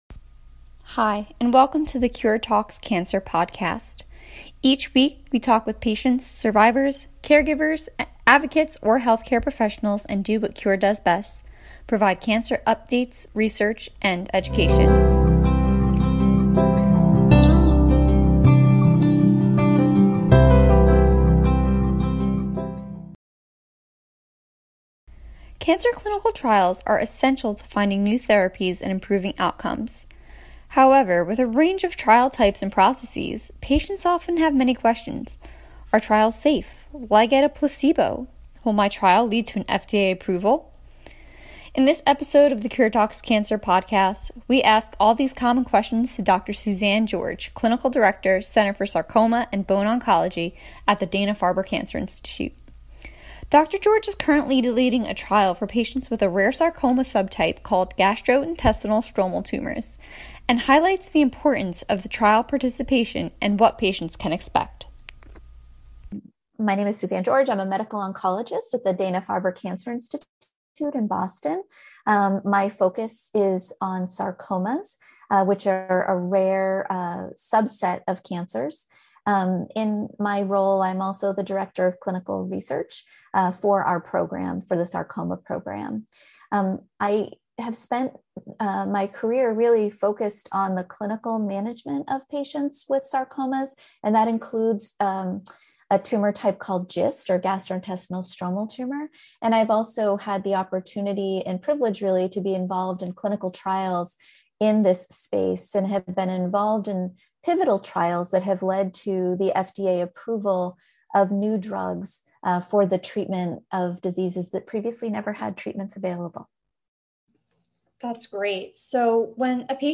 Will patients be given a placebo? Are clinical trials safe? An expert answers these questions and more.